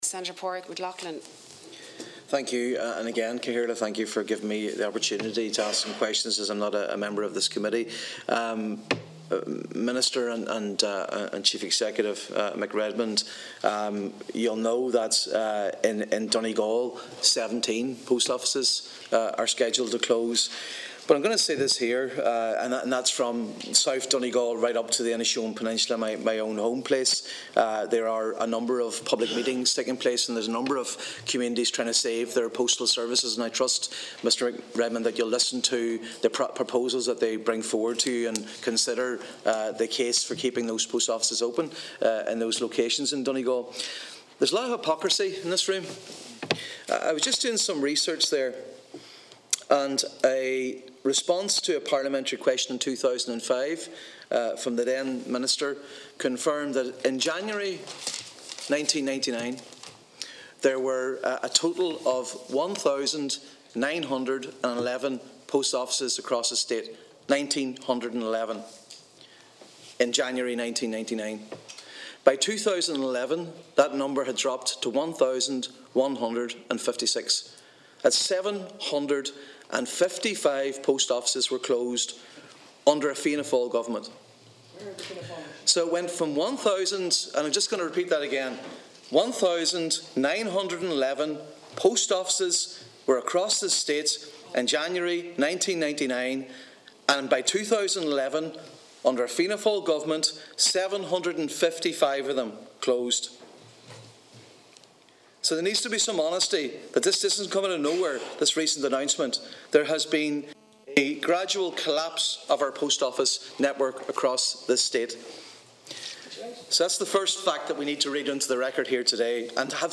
Speaking at the Oireachtas Committee on Communications, Senator Padraig MacLochlainn revealed that from 1999 to 2011, almost 40% of the post office network in Ireland was closed.